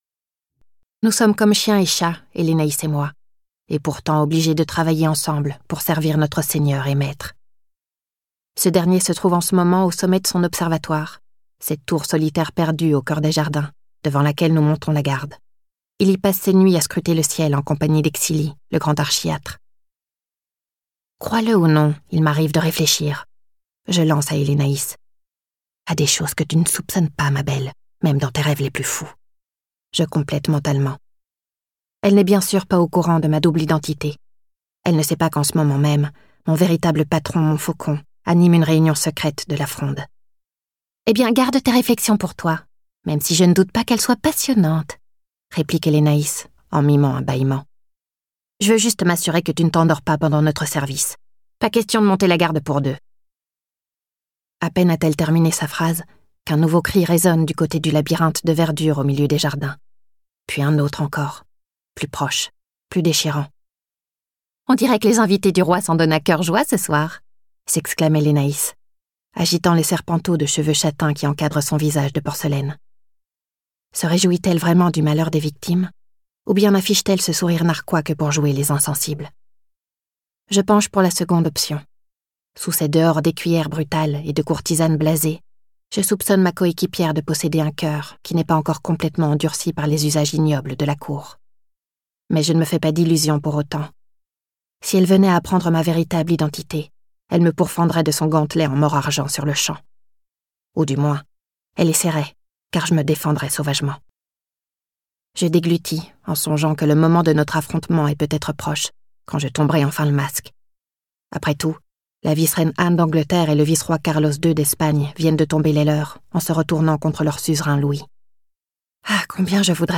Télécharger le fichier Extrait MP3